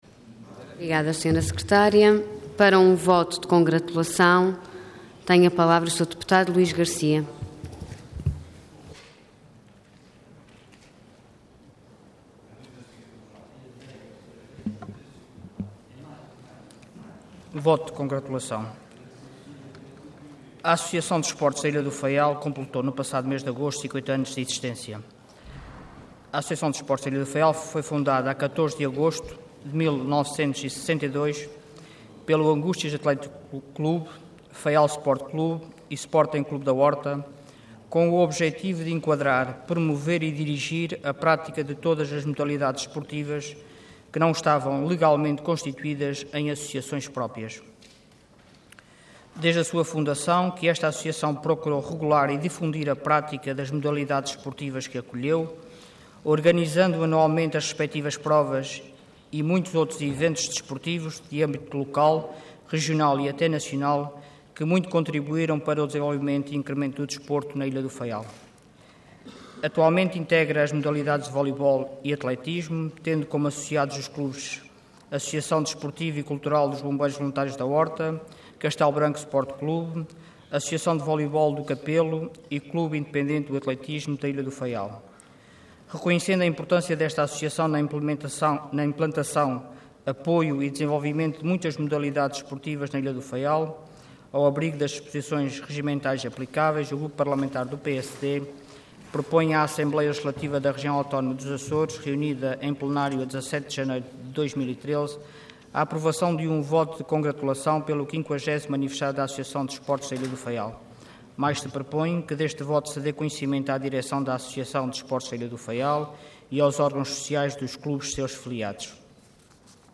Intervenção Voto de Congratulação Orador Luís Garcia Cargo Deputado Entidade PSD